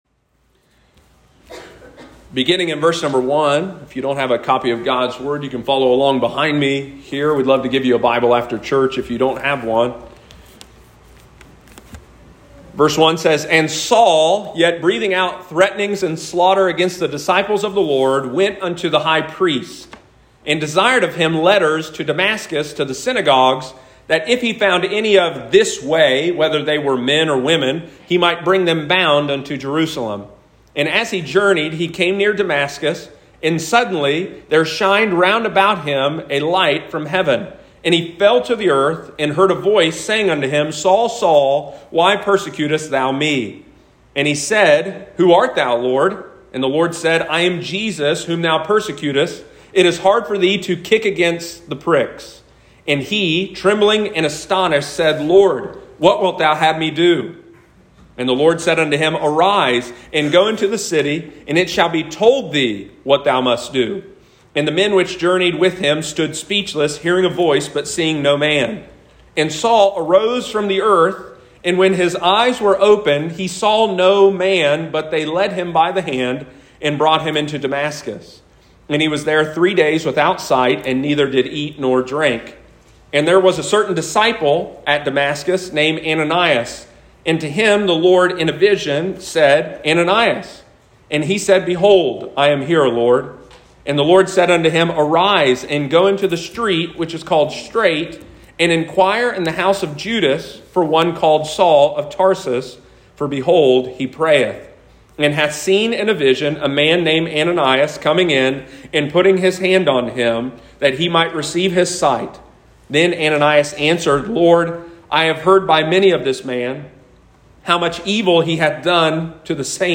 The conversion of Saul may be the most important even in Christianity outside of the life, death and resurrection of Christ. Only the gospel has the power to take Saul from a murderer to a missionary. Sunday morning, May 15, 2022.